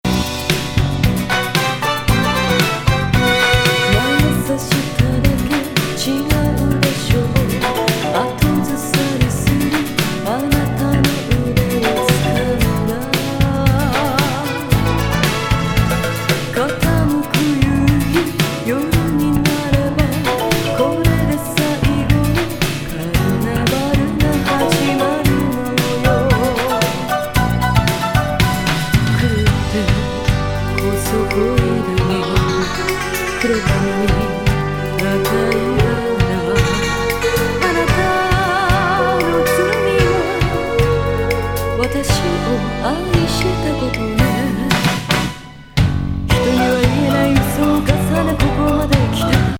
エスノ・エレクトリック歌謡B面